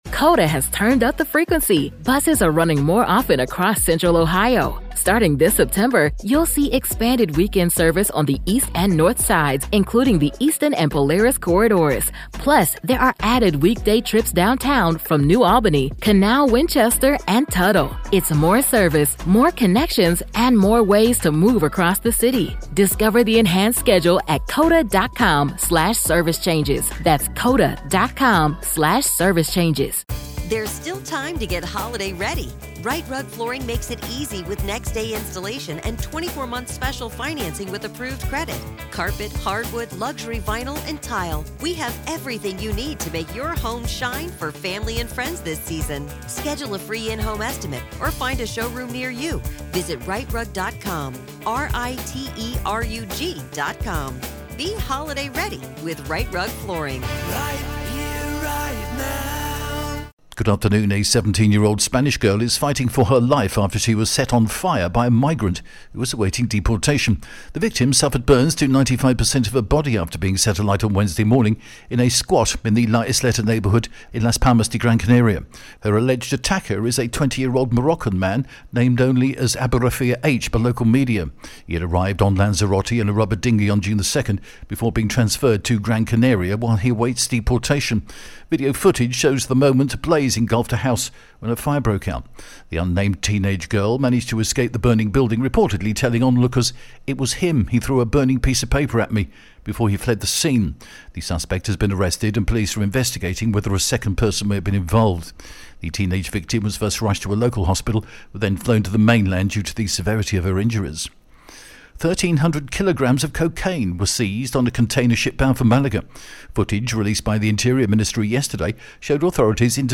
TRE is the only broadcaster in Spain to produce and broadcast, twice daily its own, in house, Spanish and local news service in English...and we offer this to you as a resource right here, and on the hour at tre.radi...